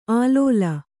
♪ ālōla